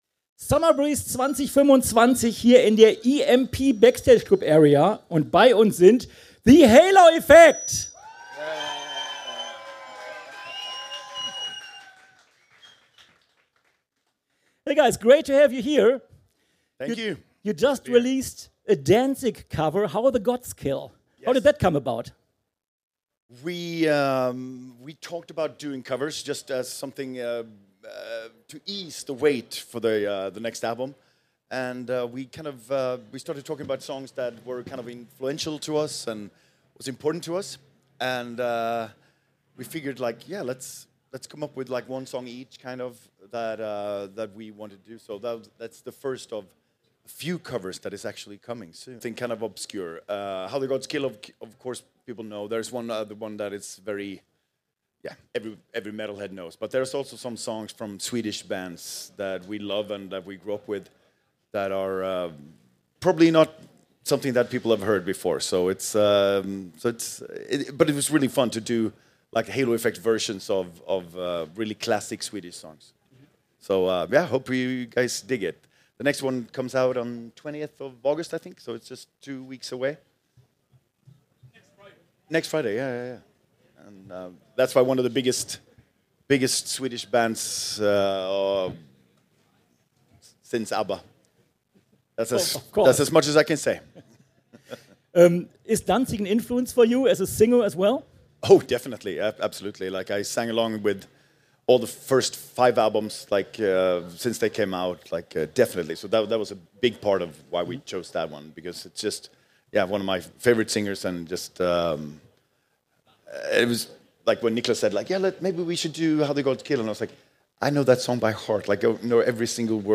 Summer Breeze 2025 Special - The Halo Effect - Live aus der EMP Backstage Club Area